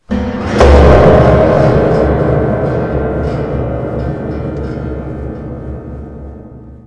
钢琴掉下楼梯
描述：这是在一架被打的旧钢琴上录制的。
标签： 崩溃 向下 阴森恐怖 现场录音 外放 钢琴 扣杀 楼梯
声道立体声